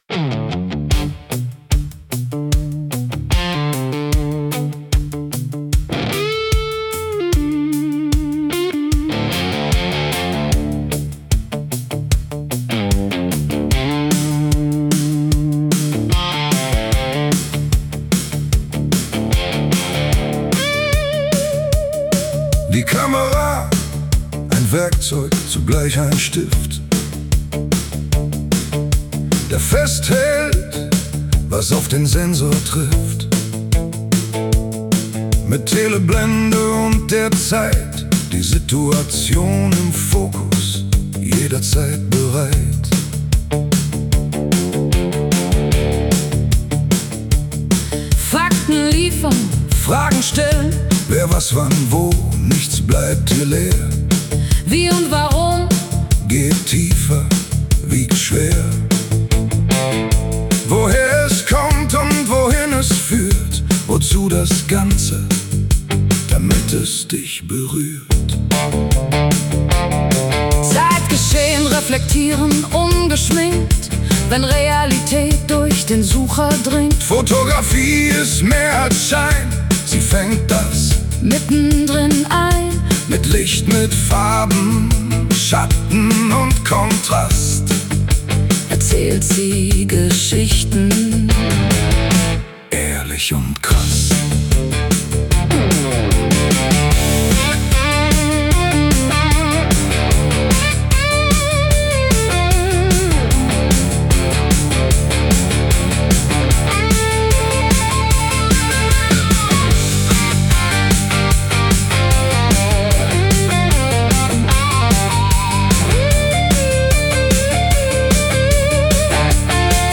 Ein Zusammenspiel aus rauem Rock-Oldie-Sound und eindringlicher Bildsprache, das den Blick schärft und Haltung zeigt.
Der Song führt durch die Geschichte, mal treibend, mal nachdenklich.
Musik: AI-generiert mit Suno AI Music
1_under_fire_swamp_rock (2).mp3